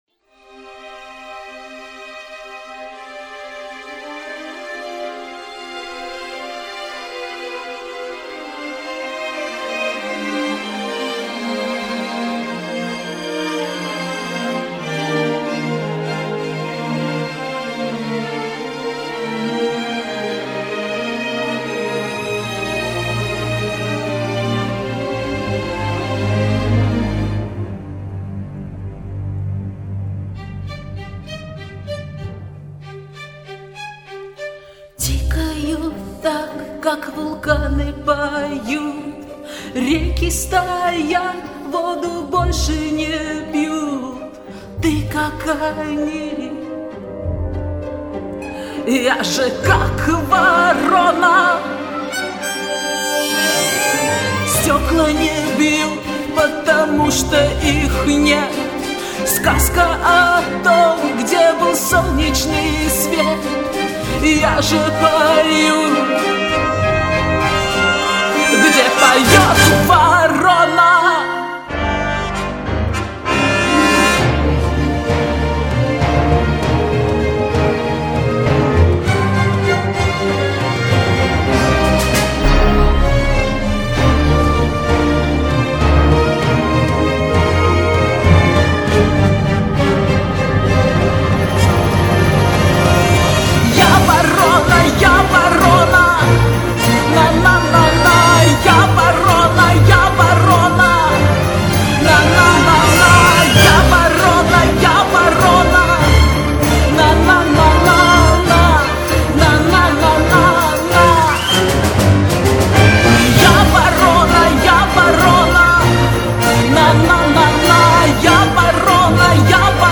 как тебе симфонический оркестр